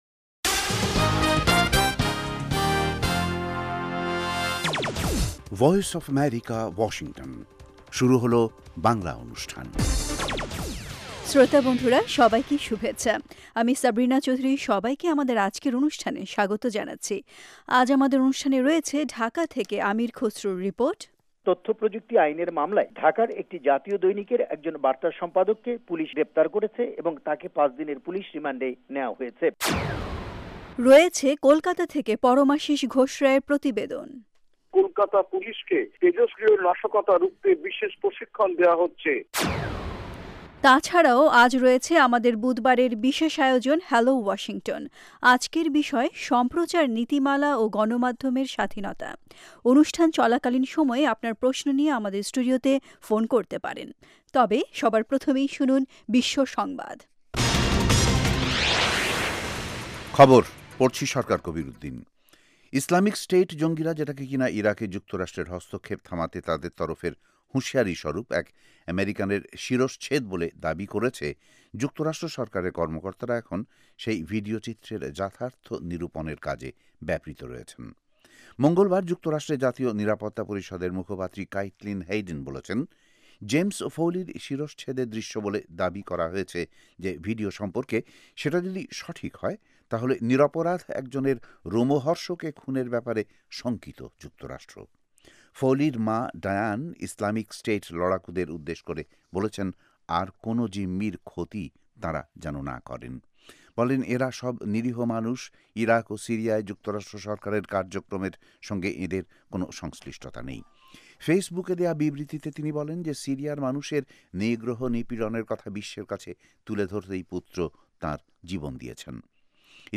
News 1600 (Daily Program)